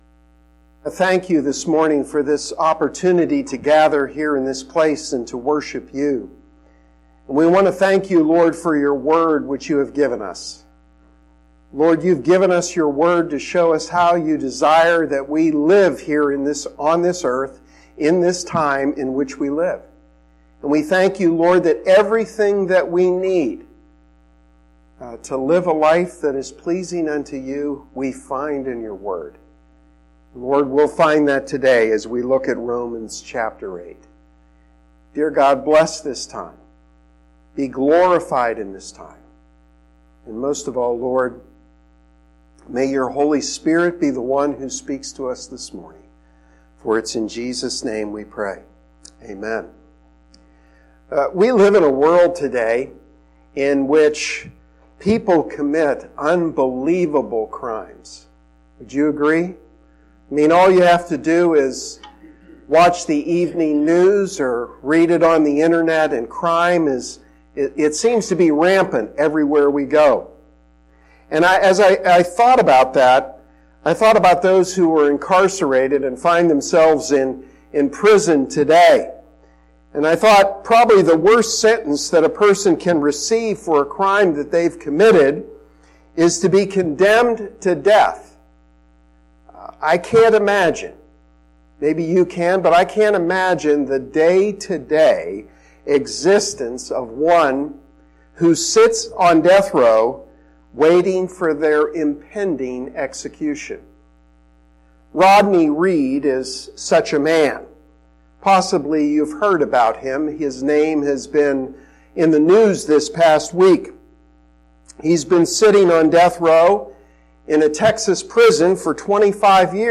Text: Romans 8:1-4 Download Sermon Audio